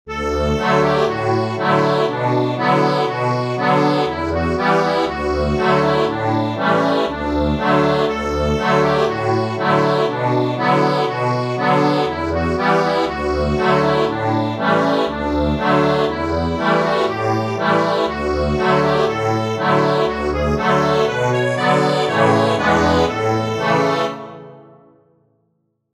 Instrument: accordion
Easy accordion arrangement plus lyrics.